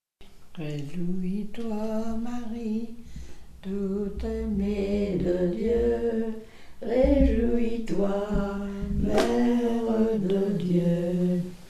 circonstance : cantique
Genre strophique
Pièce musicale inédite